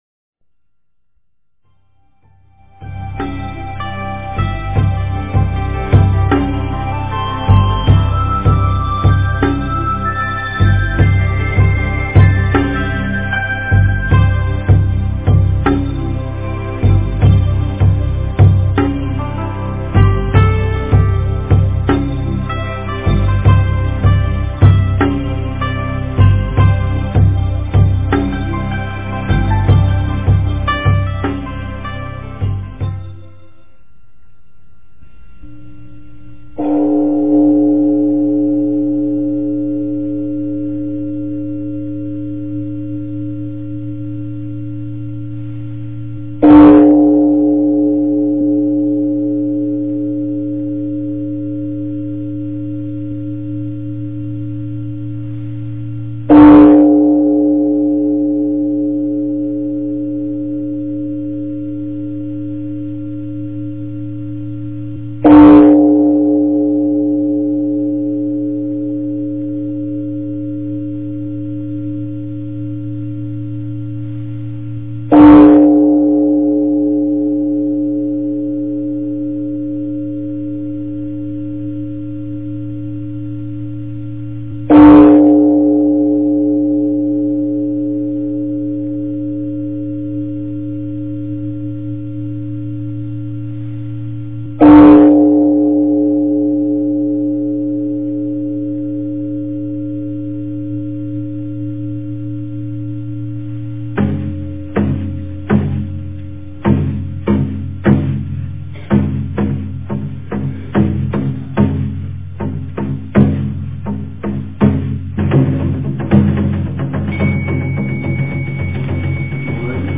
早课--临济宗净觉山光德寺
早课--临济宗净觉山光德寺 经忏 早课--临济宗净觉山光德寺 点我： 标签: 佛音 经忏 佛教音乐 返回列表 上一篇： 般若菠萝蜜颂--佚名 下一篇： 般若波罗蜜多心经--诵念快版 相关文章 文殊菩萨十大愿.念诵--普寿寺梵呗 文殊菩萨十大愿.念诵--普寿寺梵呗...